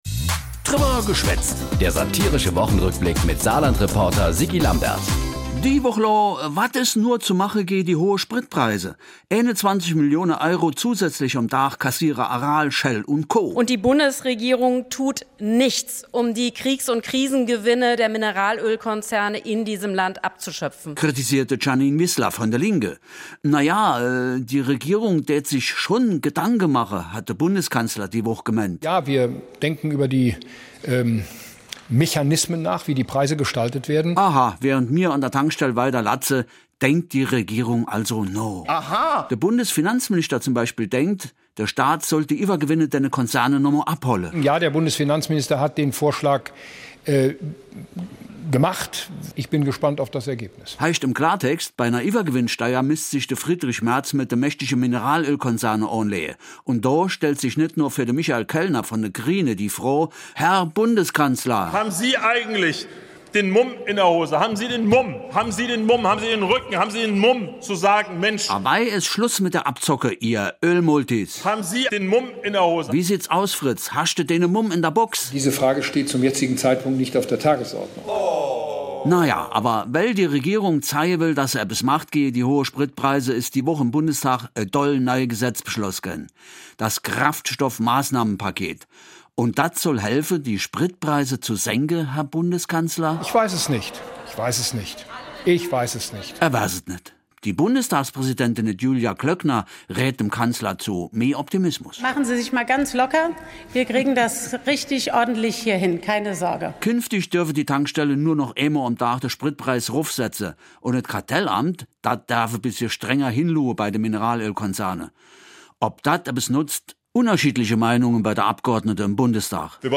Satirischer Rückblick auf die Ereignisse der Woche jeweils samstags (in Dialekt)